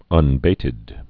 (ŭn-bātĭd)